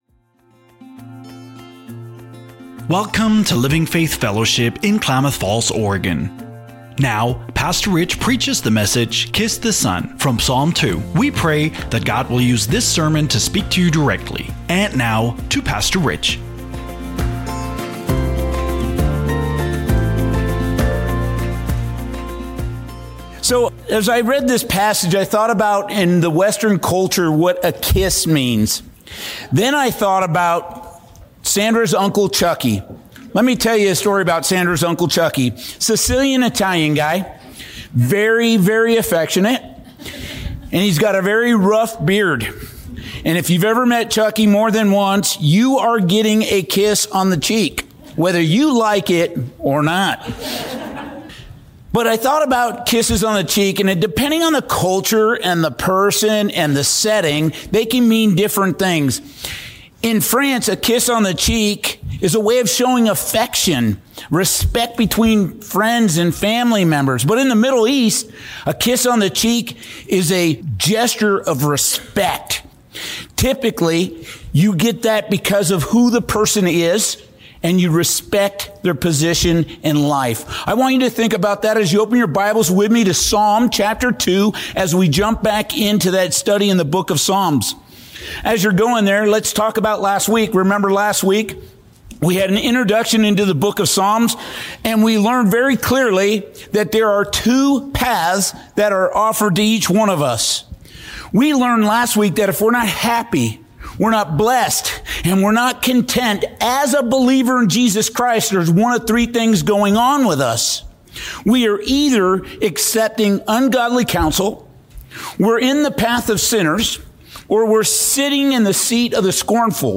41:36 Download WATCH LISTEN SERMON SERMON NOTES Listen on Podcast Apple Podcasts Spotify Amazon Music